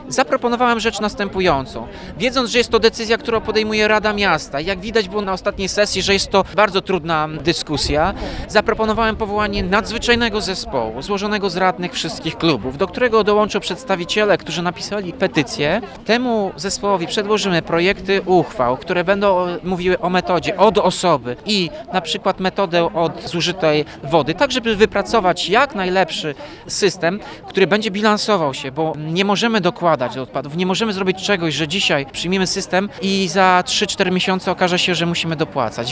Tomasz Andrukiewicz, prezydent Ełku zapowiada chęć dialogu.